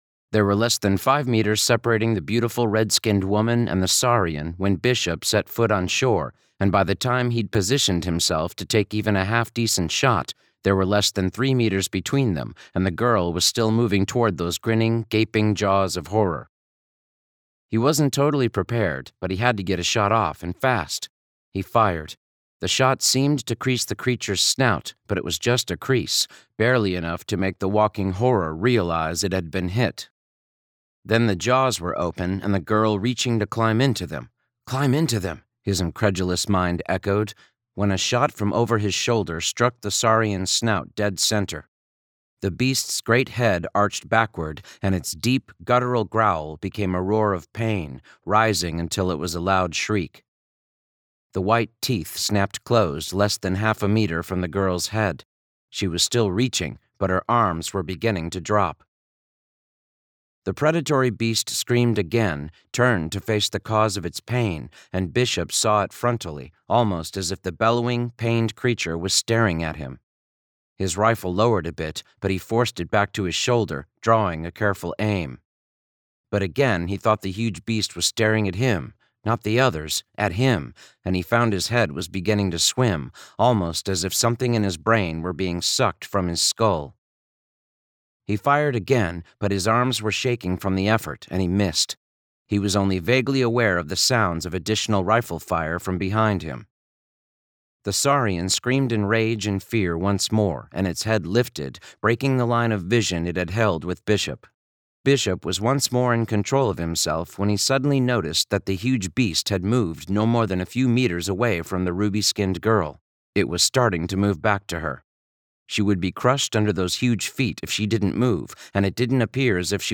THIS IS AN AUDIOBOOK